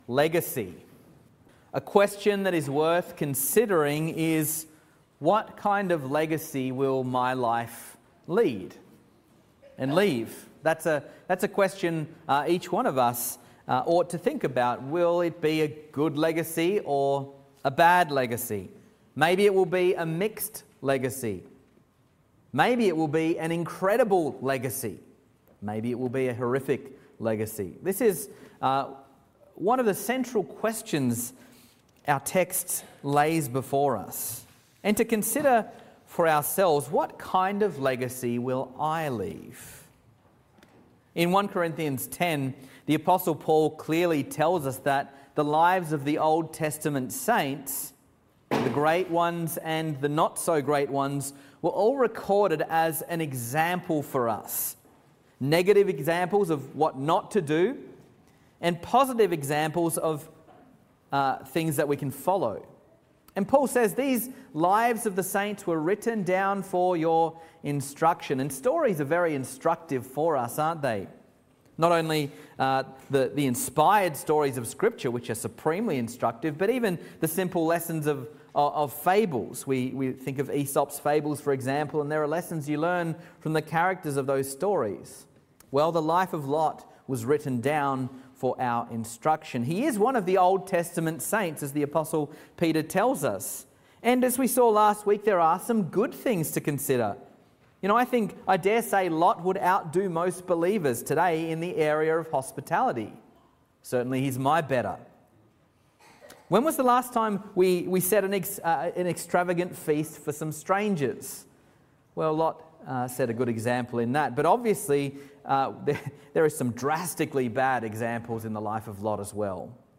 Sermons | Reformed Church Of Box Hill